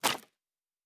Weapon UI 08.wav